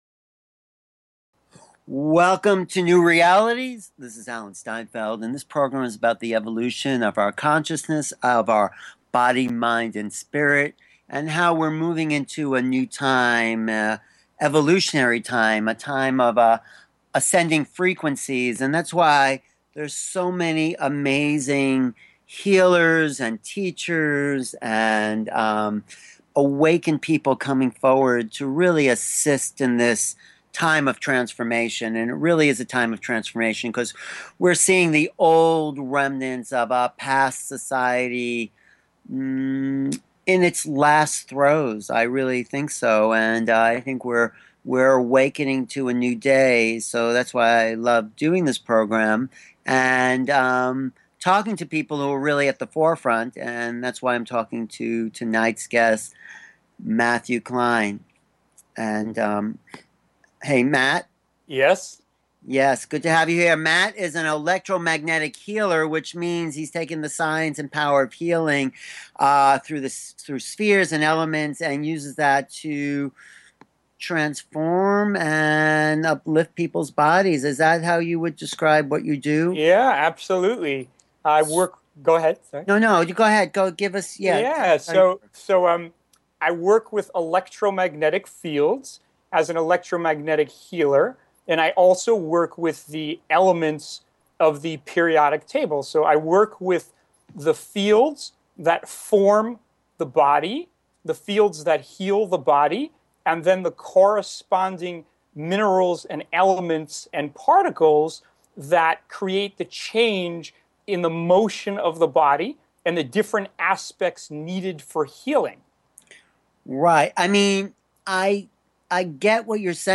Talk Show Episode
Interview